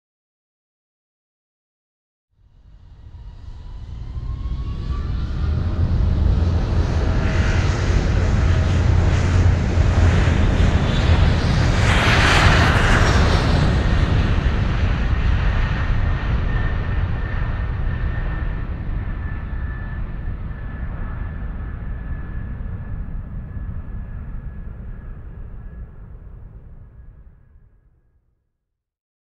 دانلود صدای پرواز یا بلند شدن هواپیما 1 از ساعد نیوز با لینک مستقیم و کیفیت بالا
جلوه های صوتی